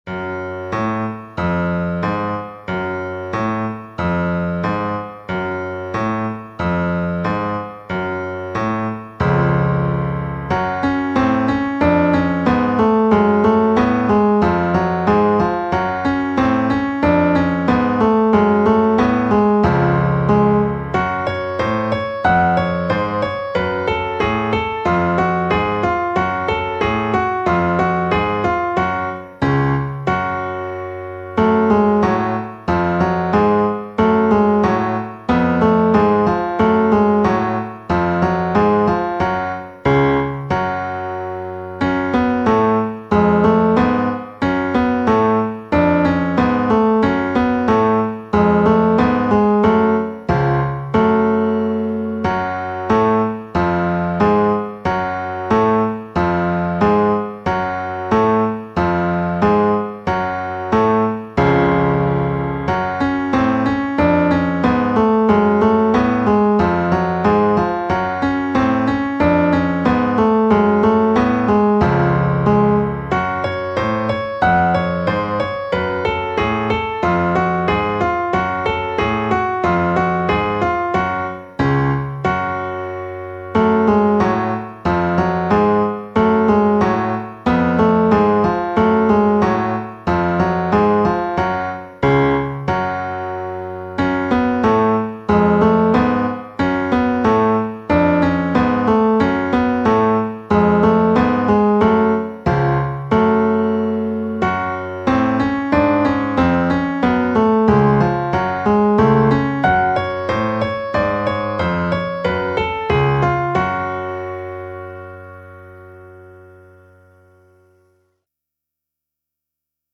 【用途/イメージ】　怪談　都市伝説　不気味　悲しげ
ピアノ曲